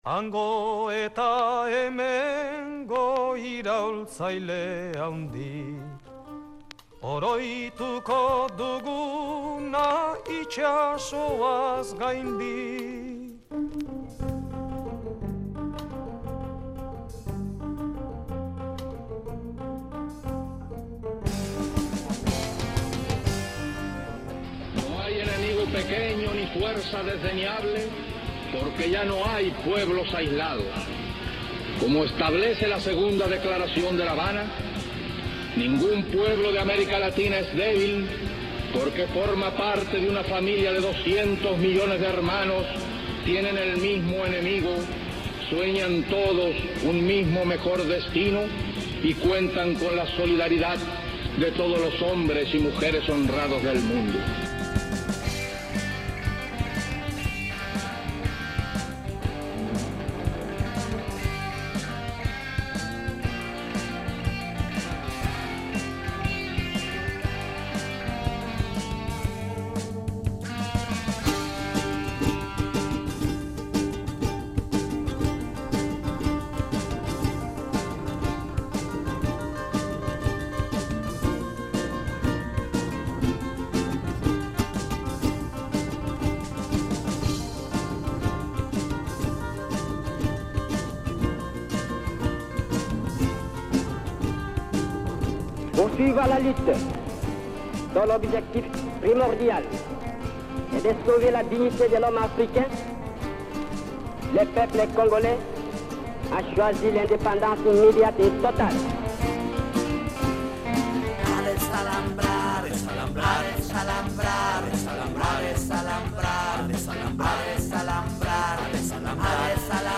Gaurko irratsaioa ahsteko asteko berri garrantzitsuenak jorratu ditugu: Greziar gobernu berria, Kurdistango erresistentzia, Ukraniako egoera, Yemen…